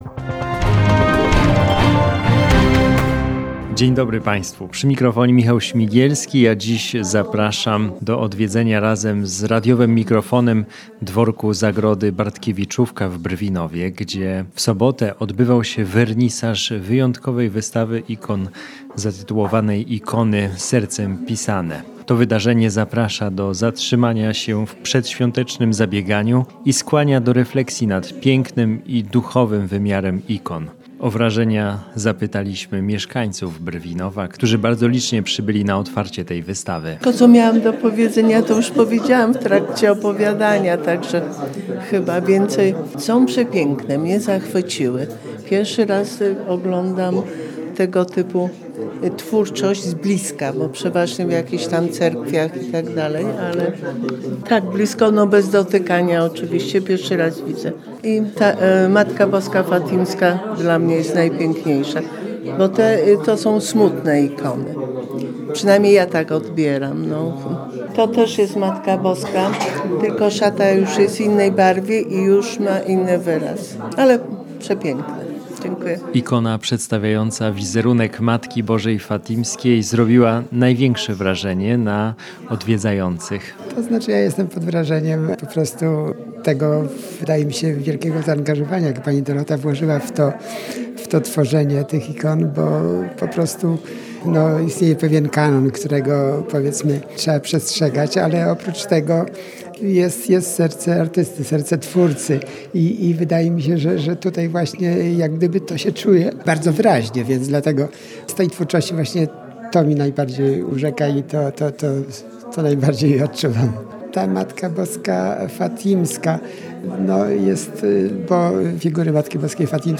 Na antenie radia Niepokalanów pojawiła się relacja z wernisażu wystawy Ikony Sercem Malowane odbywającej się Dworku Zagroda w Brwinowie.